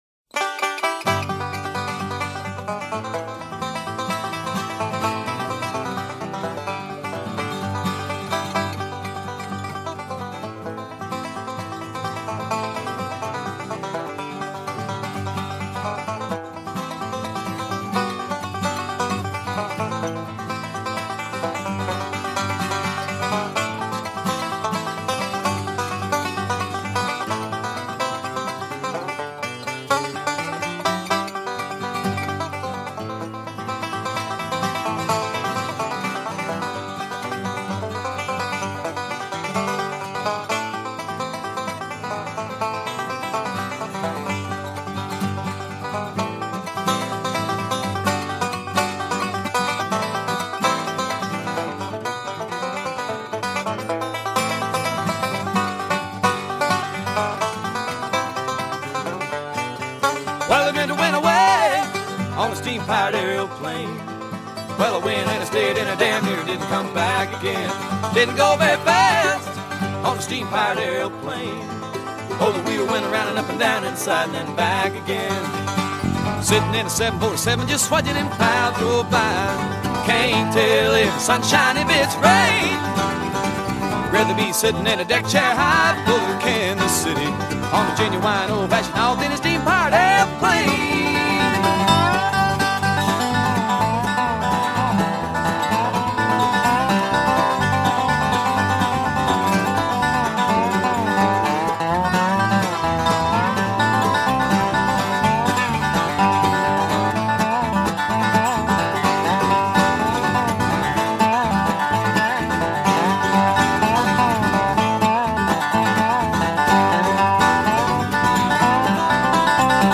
mixed traditional bluegrass styles with jazz and rock